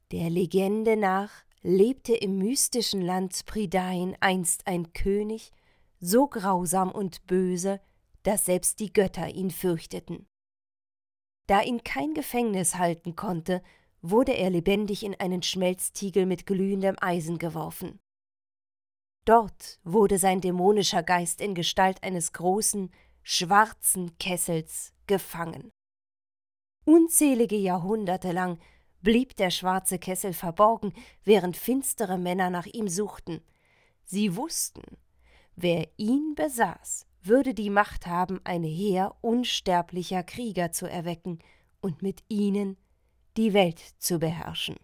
sehr variabel, hell, fein, zart, markant
Mittel minus (25-45)
Eigene Sprecherkabine
Game
Audiobook (Hörbuch), Game, Narrative, Tale (Erzählung)